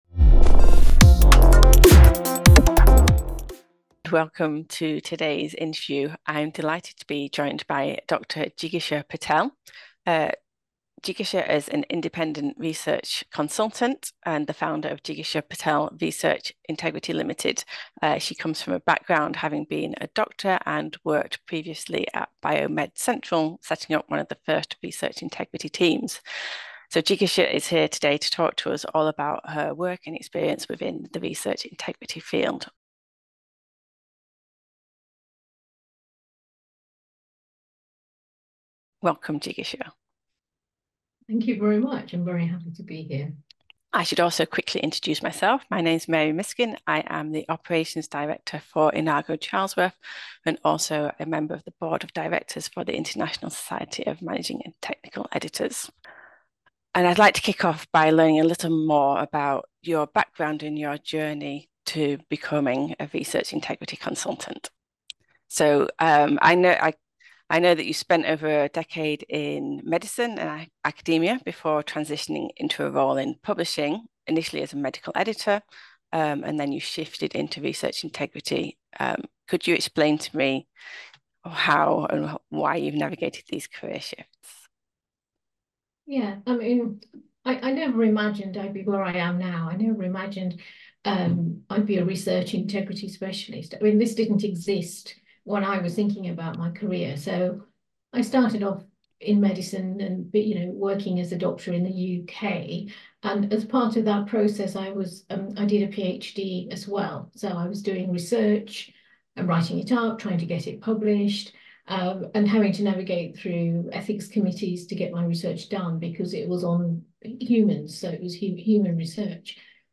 Tune in for a thought-provoking conversation that will shape your approach to research integrity in the rapidly evolving publishing landscape.